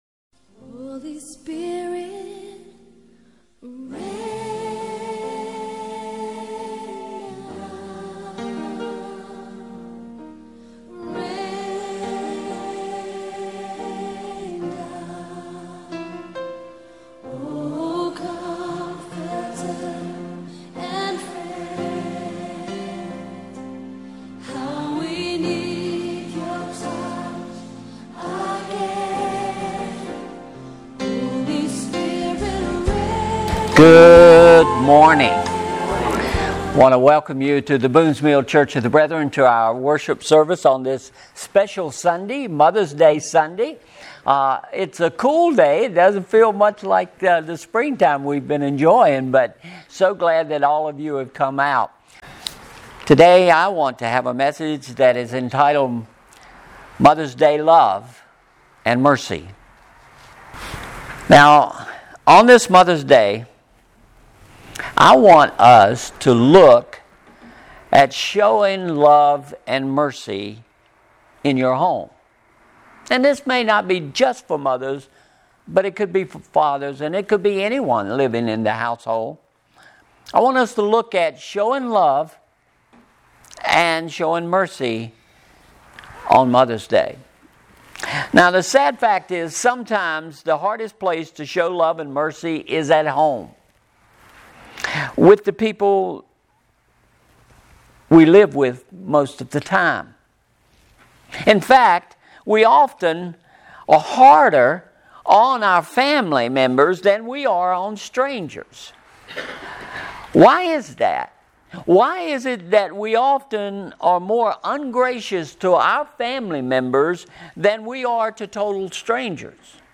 Message Synopsis ------------------------------------- On this Mother’s Day, I want us to look at “Showing Love and Mercy” in your home.